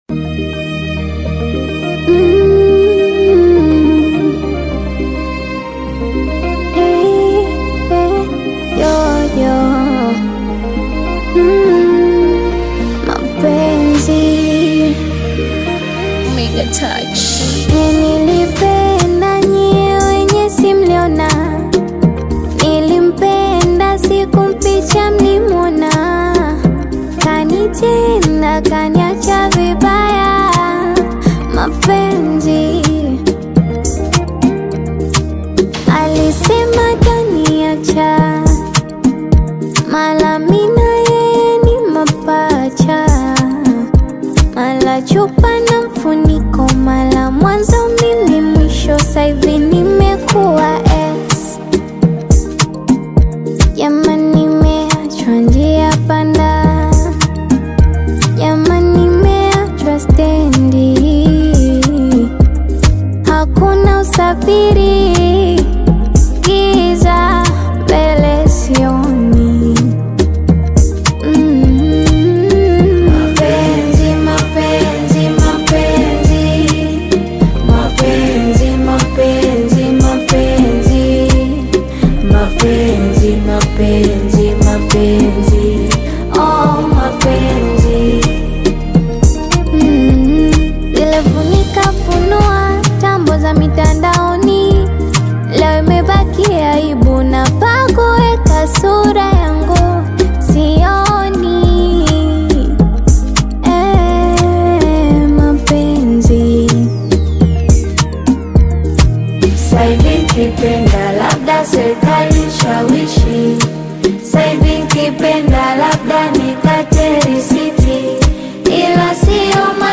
Tanzanian Bongo Flava/Afro-Pop single
blends melodic hooks with modern Afro-inspired rhythms